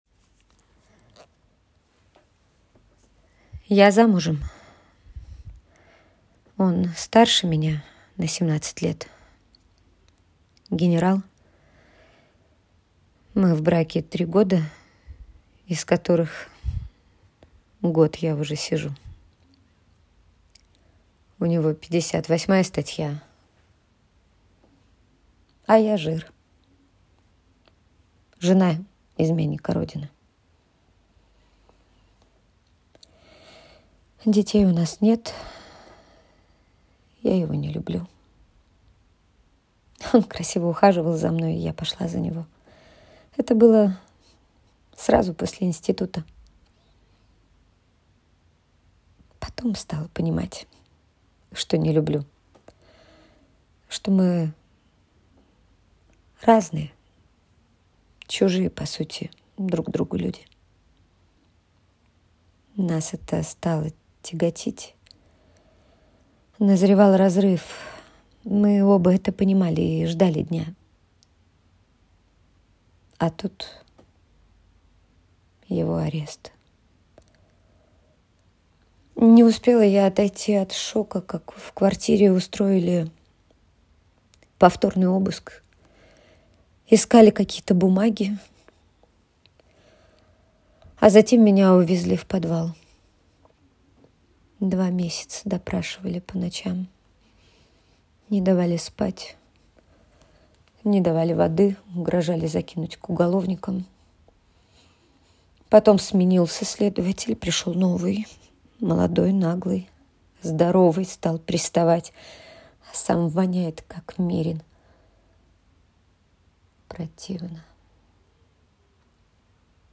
Демо озвучивания